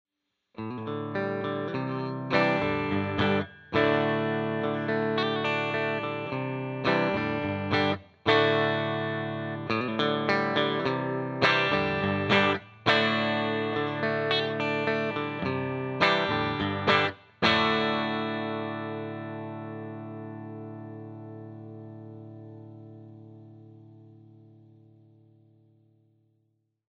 65R/65T used together (middle position)
65R-65T neck and bridge.mp3